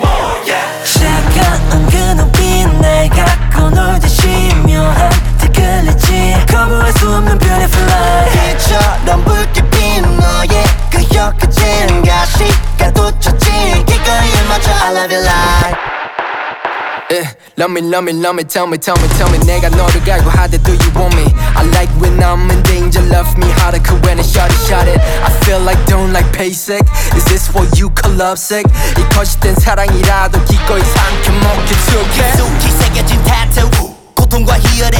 Жанр: Поп / K-pop